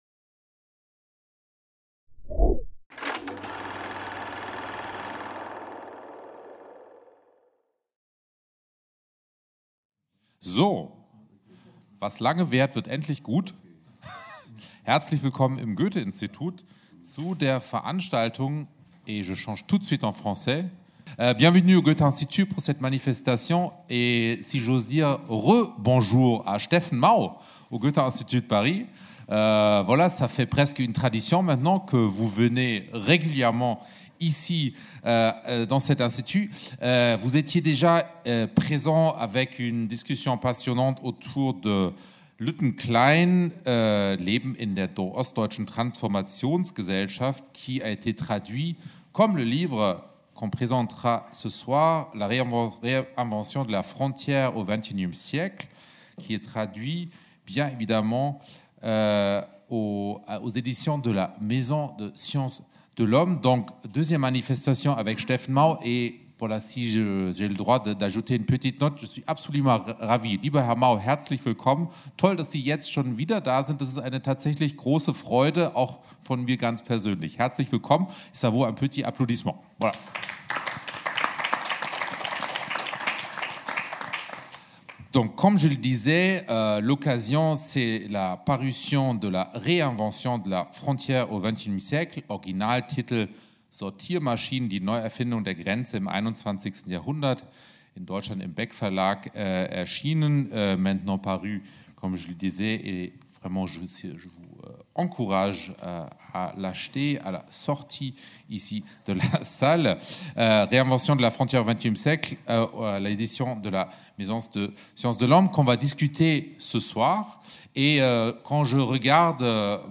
Rencontre avec Steffen Mau - Buchgespräch mit Steffen Mau | Canal U